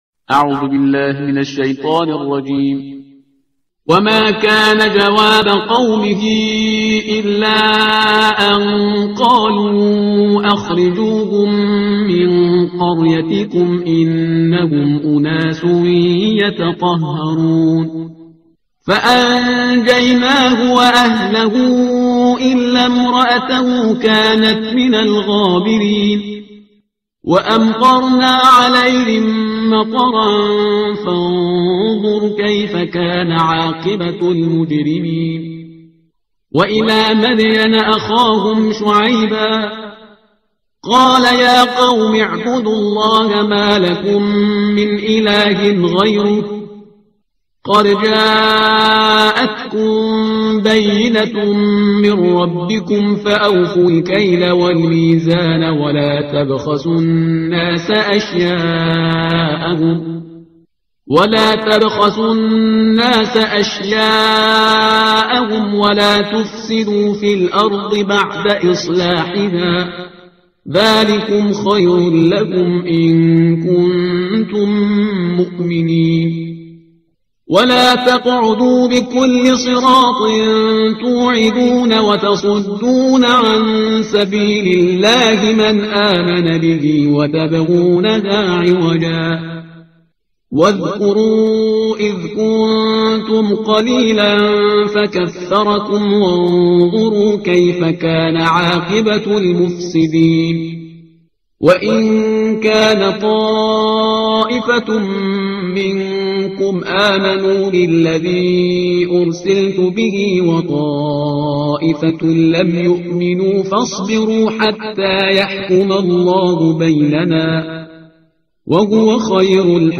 ترتیل صفحه 161 قرآن